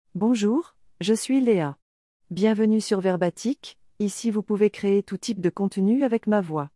Léa — Female French AI voice
Léa is a female AI voice for French.
Voice sample
Listen to Léa's female French voice.
Female
Léa delivers clear pronunciation with authentic French intonation, making your content sound professionally produced.